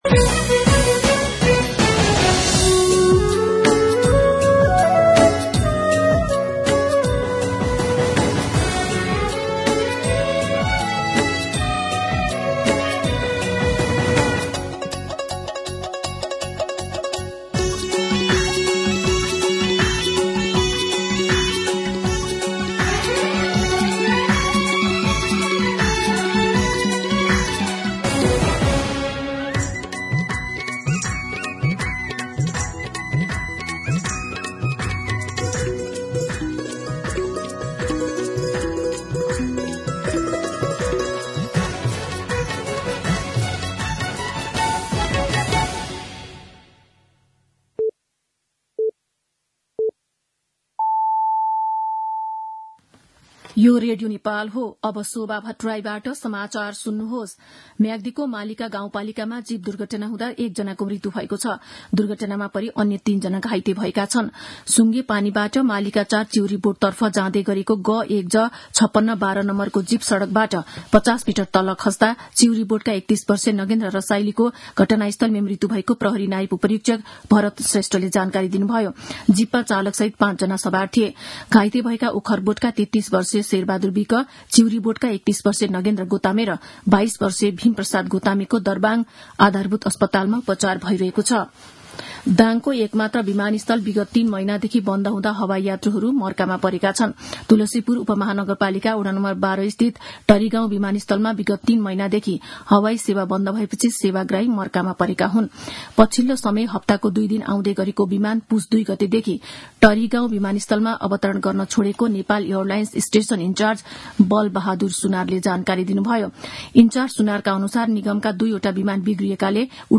मध्यान्ह १२ बजेको नेपाली समाचार : ६ चैत , २०८१